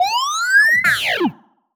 sci-fi_driod_robot_emote_14.wav